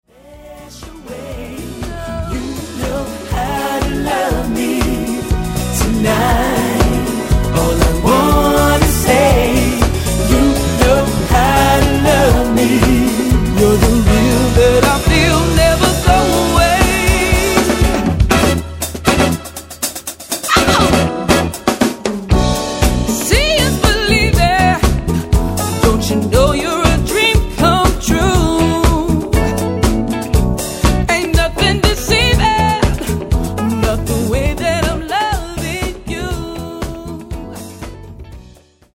往年のSOUL/FUNKクラシックを完璧に歌いこなす珠玉のカバー集を緊急リリース!